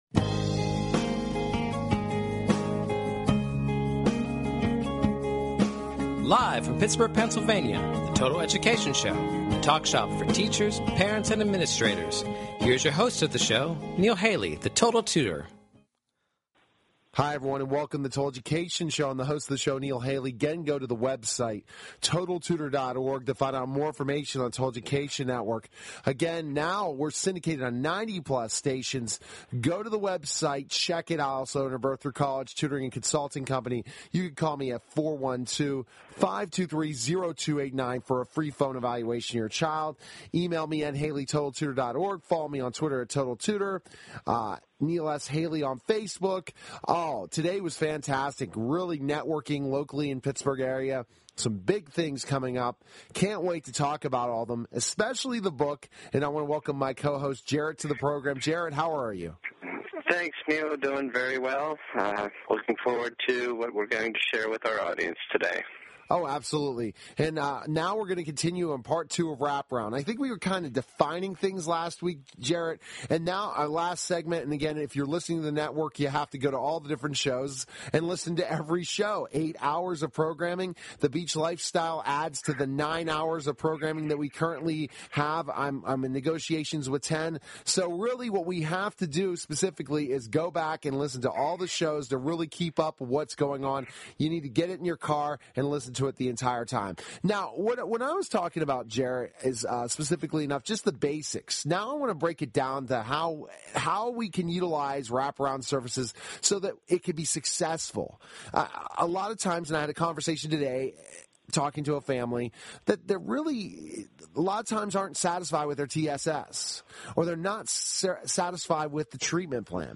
Catch weekly discussions focusing on current education news at a local and national scale.